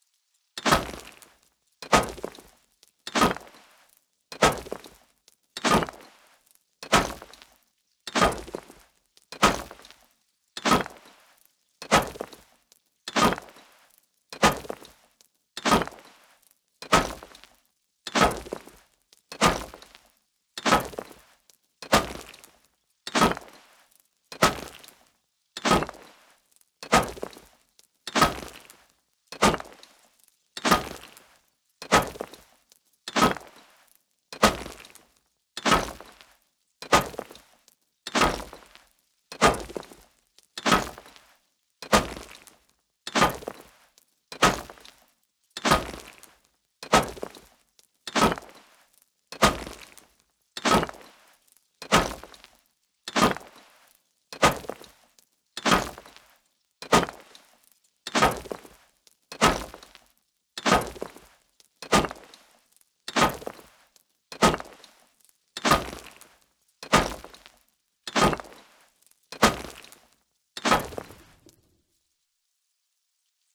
Salvaged Axe On Stone Wall